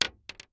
Bullet Shell Sounds
pistol_wood_7.ogg